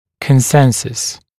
[kən’sensəs][кэн’сэнсэс]единодушие, консенсус, согласие